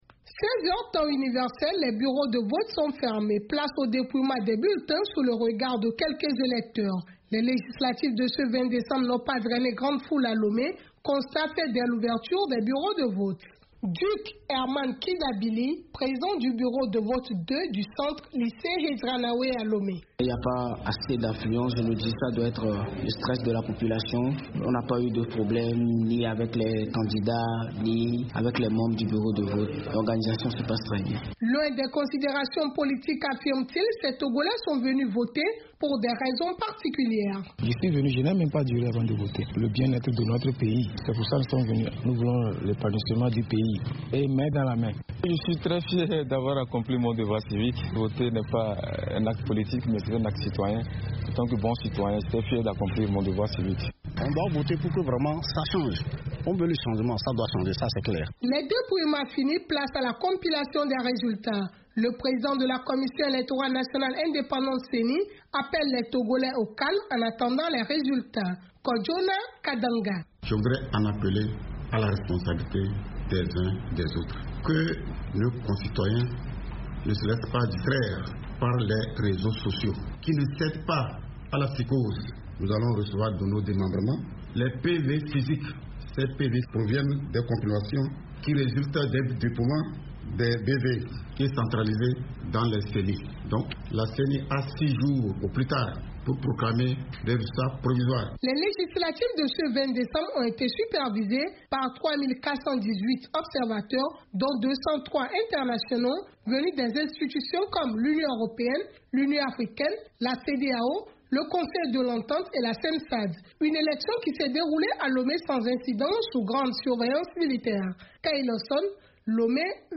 Au total, 8490 bureaux de vote ont été mobilisés pour permettre aux 3 millions d'électeurs d’accomplir leur devoir civique. Une élection qui s’est déroulée dans le calme pendant que notre correspondante à Lomé a fait le tour de quelque centre de vote dans la capitale togolaise.